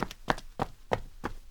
sfx_run.ogg